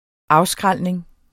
Udtale [ ˈɑwˌsgʁalˀneŋ ]